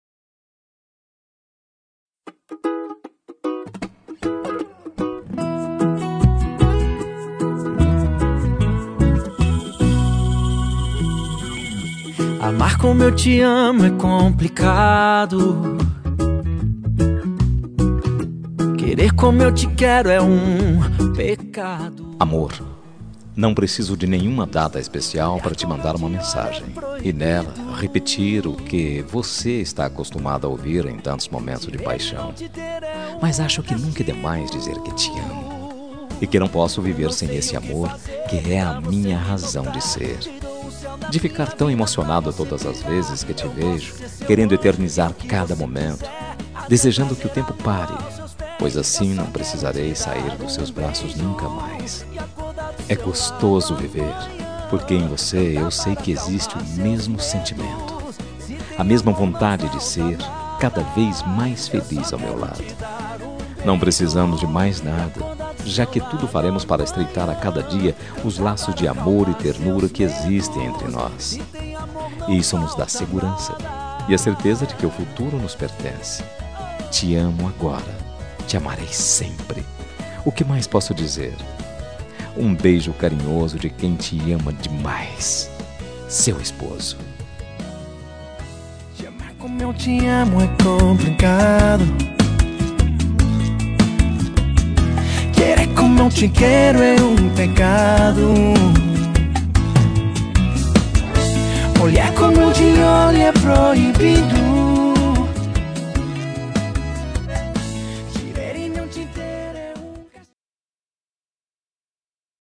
Telemensagem Romântica para Esposa – Voz Masculina – Cód: 201822